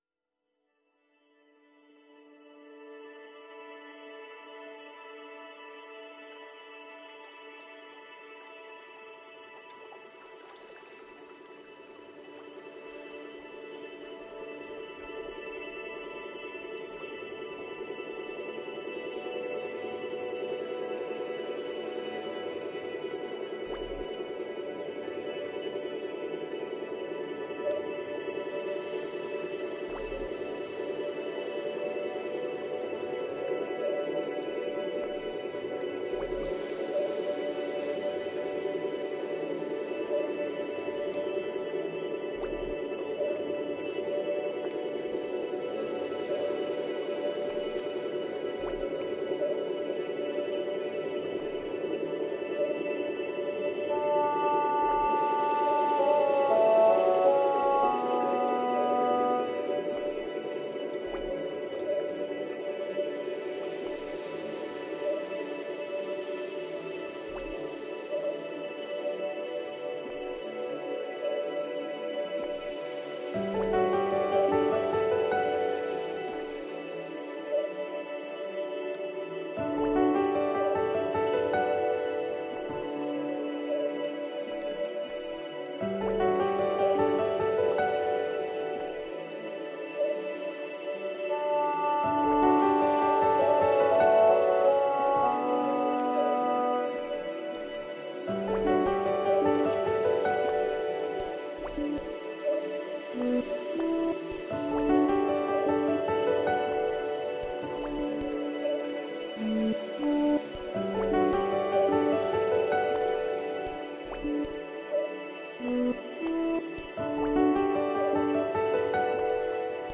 As an Electro-Acoustic artist